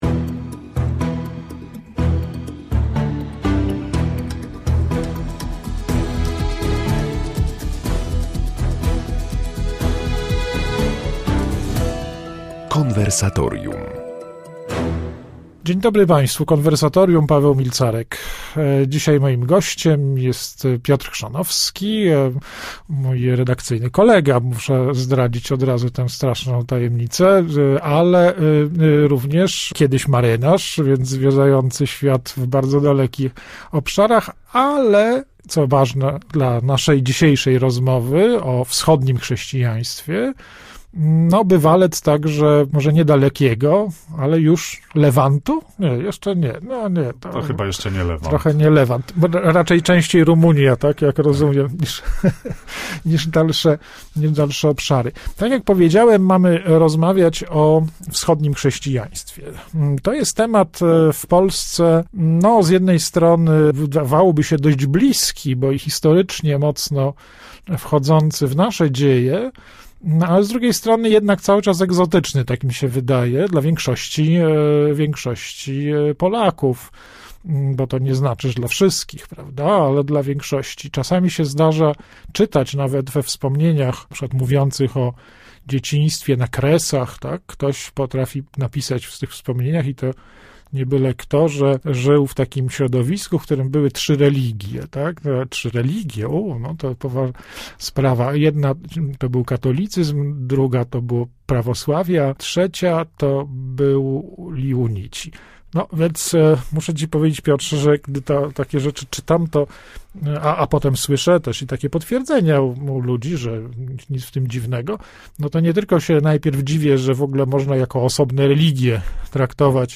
O chrześcijaństwie wschodnim rozmawiają: